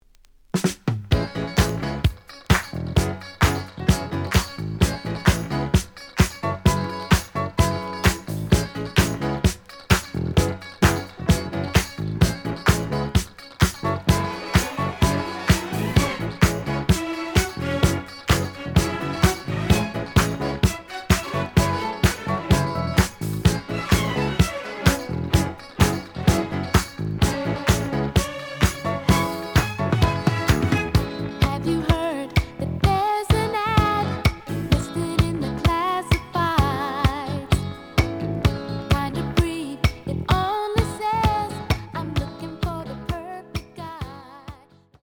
試聴は実際のレコードから録音しています。
●Genre: Disco